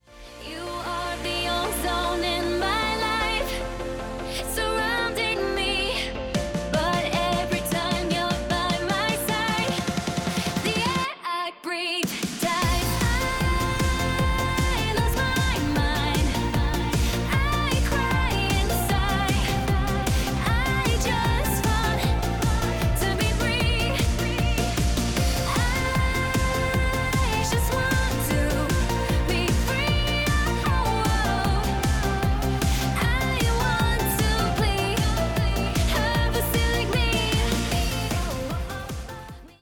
recorded in the UK and then Bangtao, Phuket
Genre: Pop.